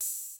Southside Open Hatz (14).wav